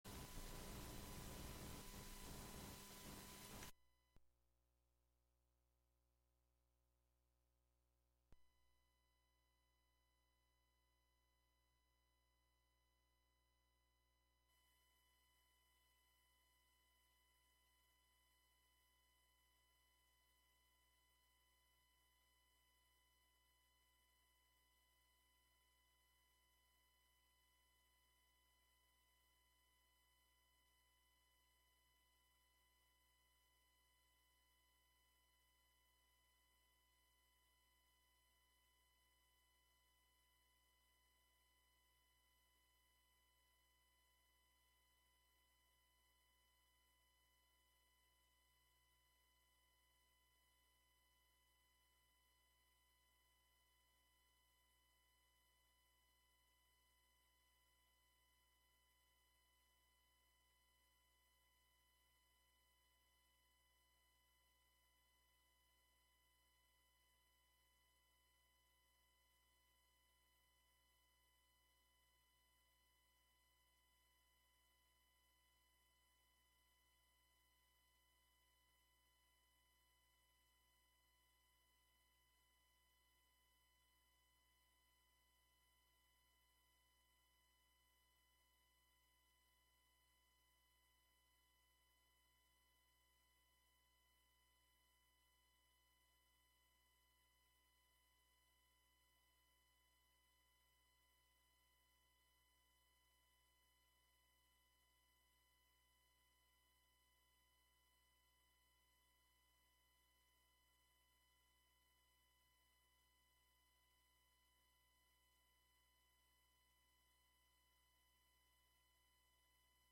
7pm Monthly program featuring music and interviews fro...
broadcast live from WGXC's Hudson studio.